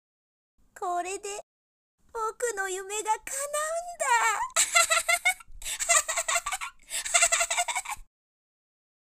】男児ゴーストセリフ nanaRepeat